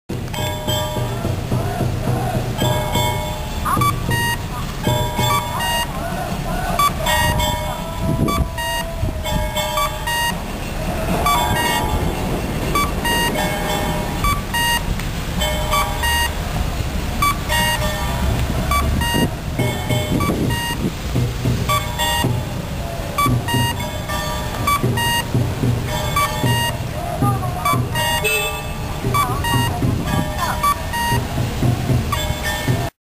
カッコーです。中津祇園祭があっていて、その音が少し入ってます。
音質は名電らしいものですね(^^)。21号などとも似た音質のようです。